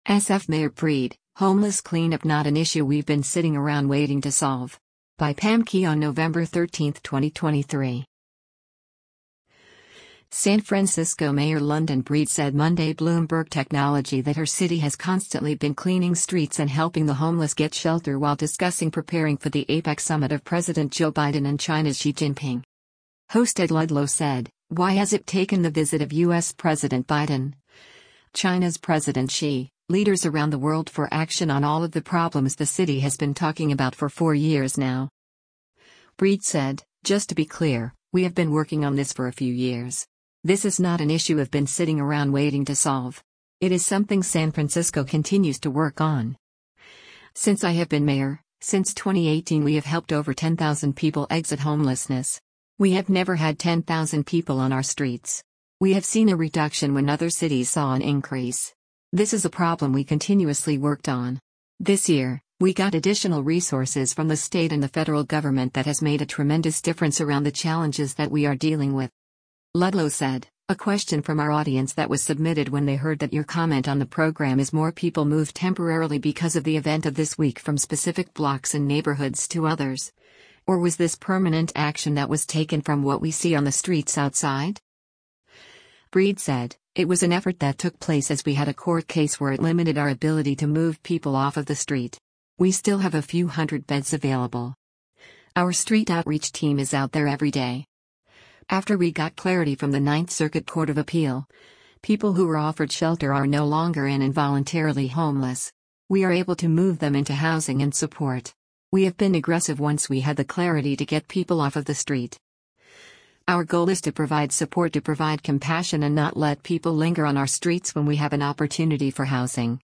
San Francisco Mayor London Breed said Monday “Bloomberg Technology” that her city has constantly been cleaning streets and helping the homeless get shelter while discussing preparing for the APEC Summit of President Joe Biden and China’s Xi Jinping.